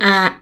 gum, hut, sun
_ a